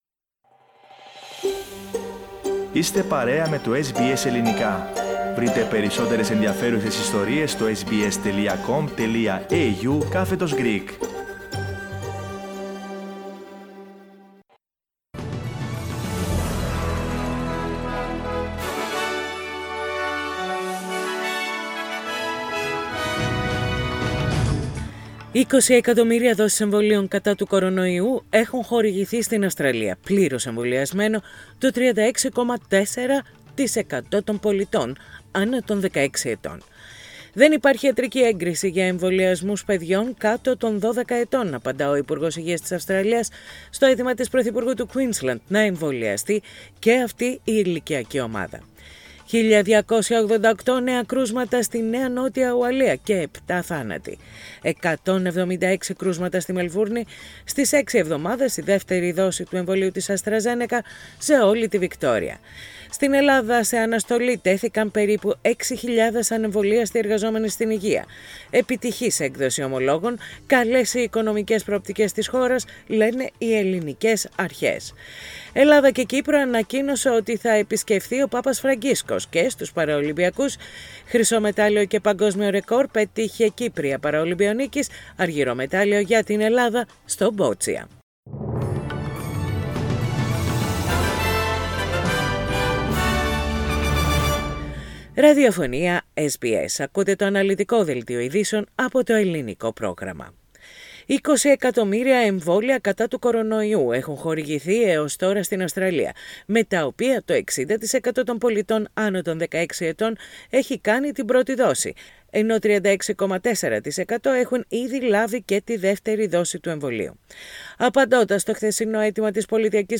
Ειδήσεις στα Ελληνικά - Πέμπτη 2.9.21